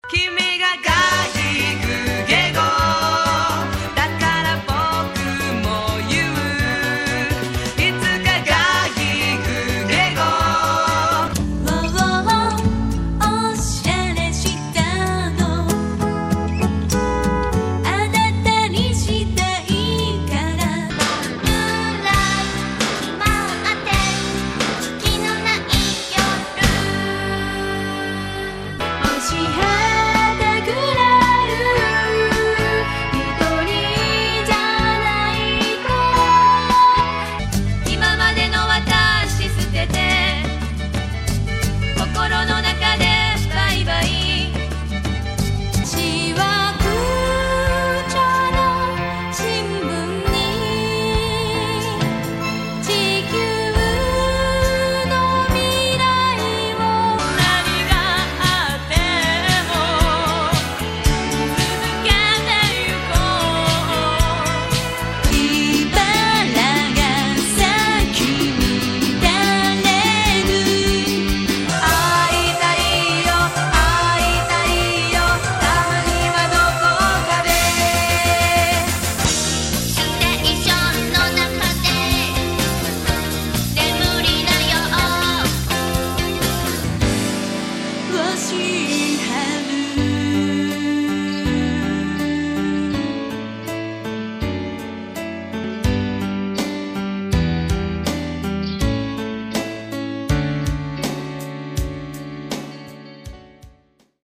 ＊Web上で人気の女の子シンガー7人によるユニット。